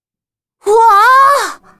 女孩惊讶哇啊音效免费音频素材下载